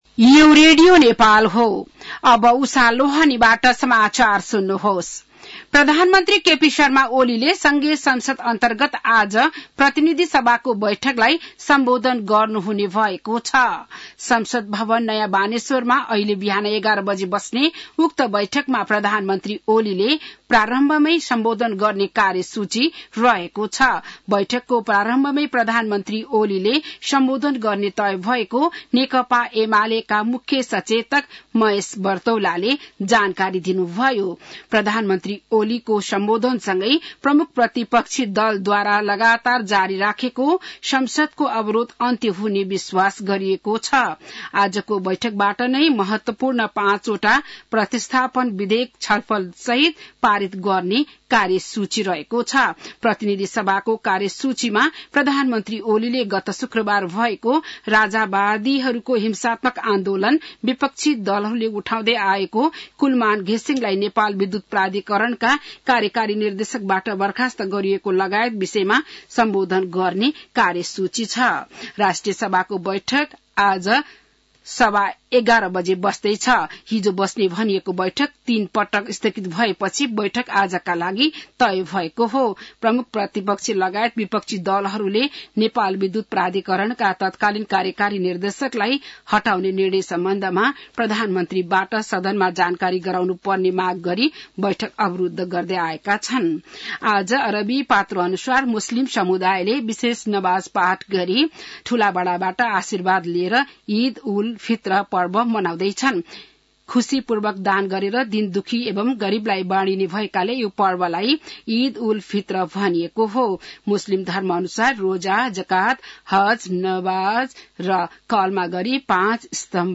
An online outlet of Nepal's national radio broadcaster
बिहान ११ बजेको नेपाली समाचार : १८ चैत , २०८१
11-am-news-5.mp3